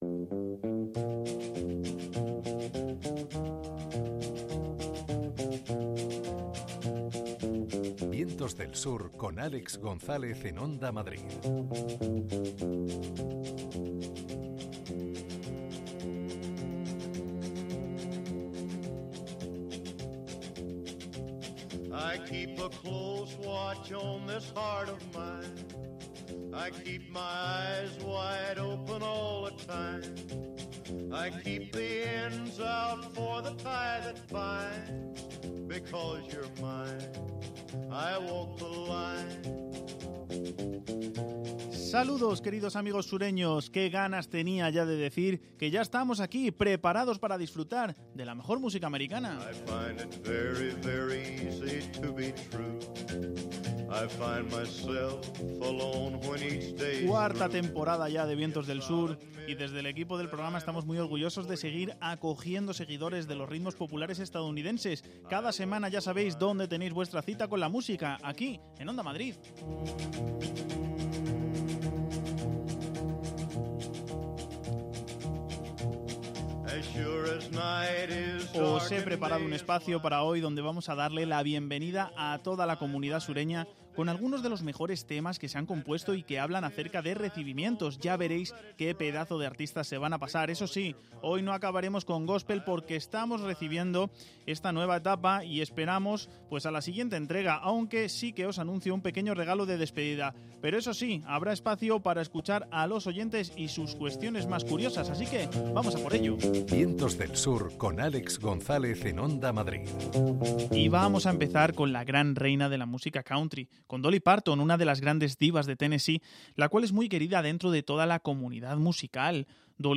un repertorio de canciones de country